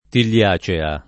[ til’l’ # kka ]